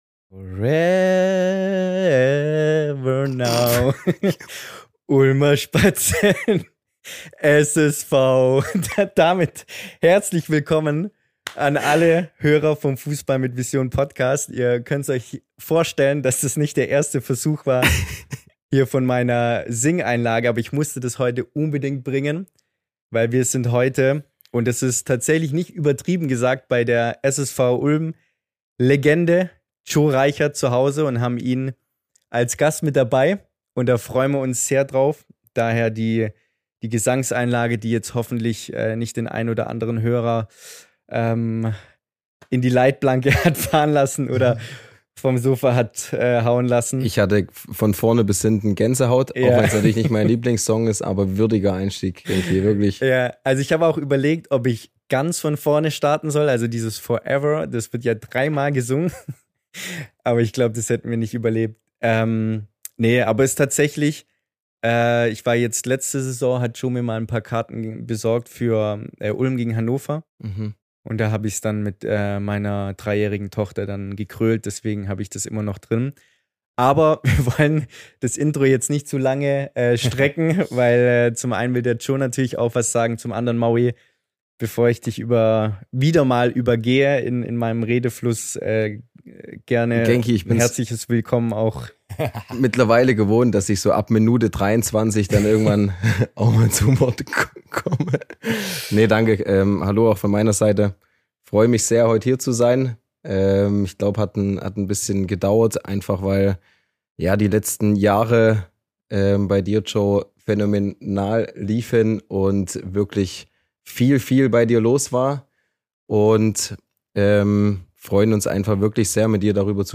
Ein ehrliches und inspirierendes Gespräch über Herz, Glaube und Fußball.